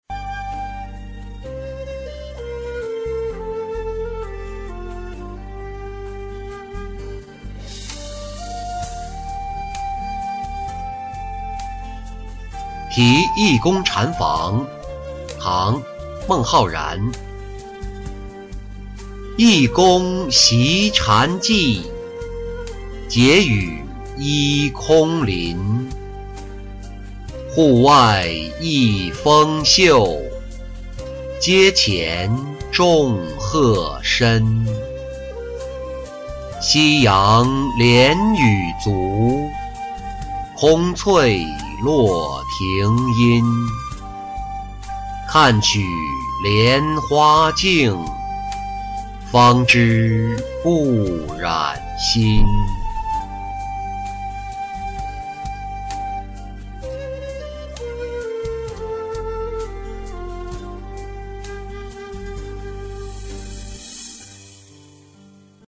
题大禹寺义公禅房-音频朗读